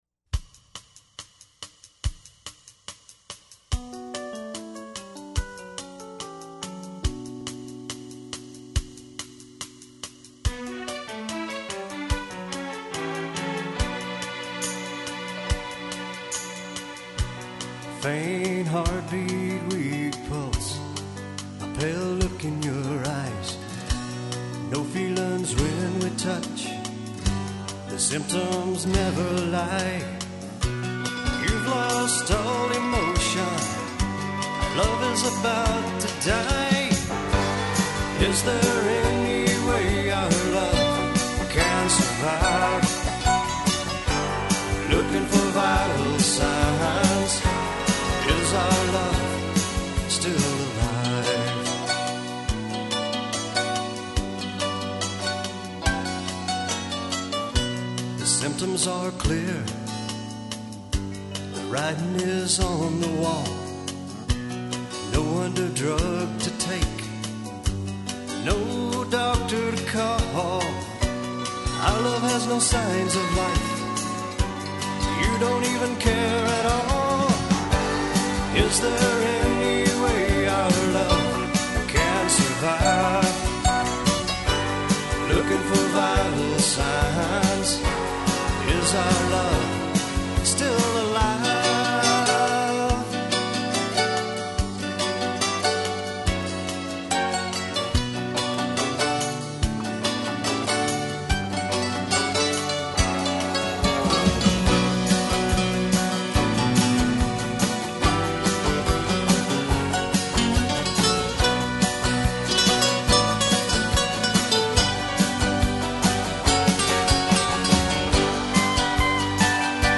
Just another dieing love song.